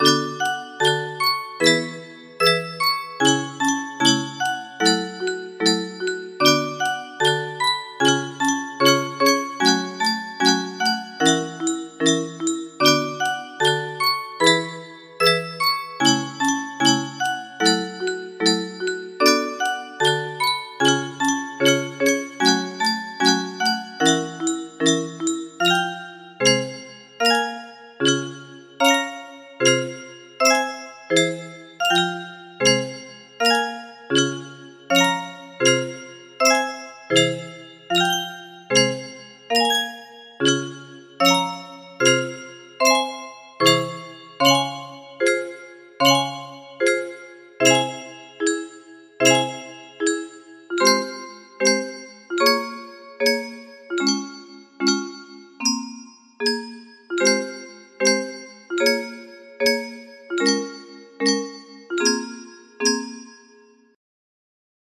BPM 150